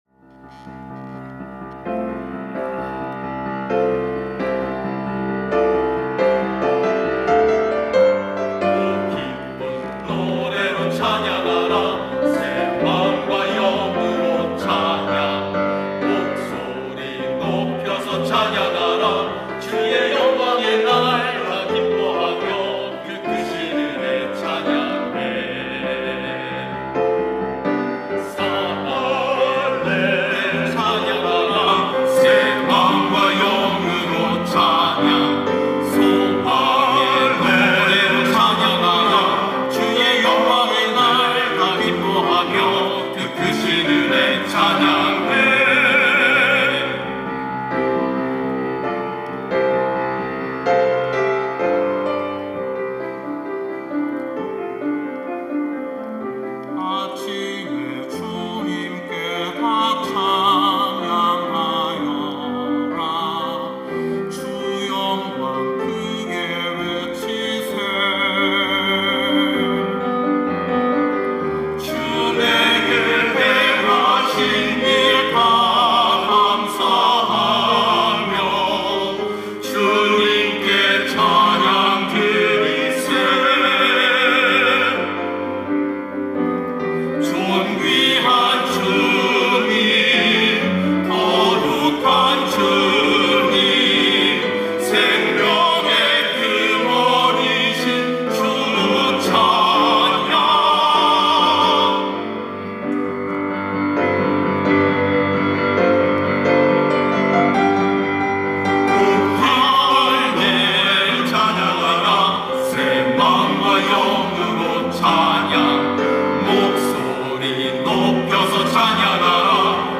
찬양대 휘오스